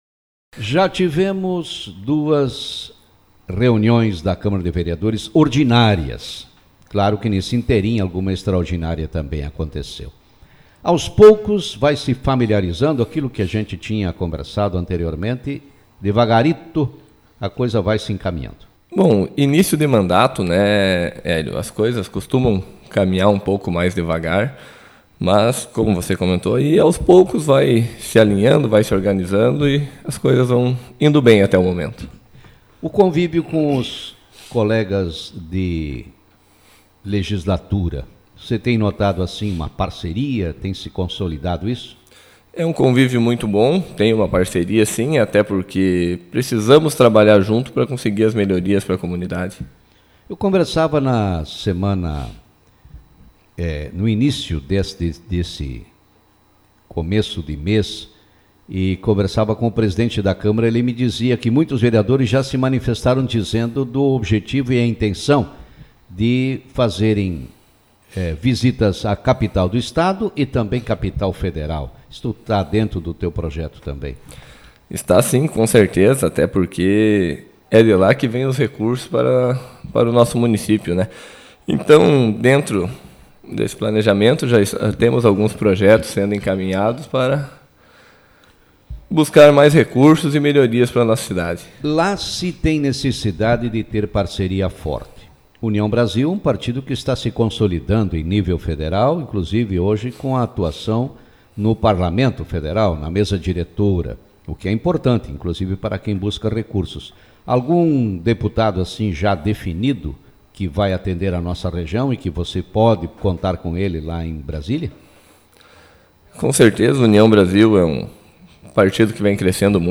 Vereador GUILHERME FERRAZ – União Brasil – avalia o início dos trabalhos no legislativo iraiense Autor: Rádio Marabá 04/02/2025 Manchete Na manhã dessa terça-feira, concedeu entrevista o Vereador GUILHERME FERRAZ – União Brasil.